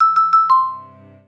dead_battery.wav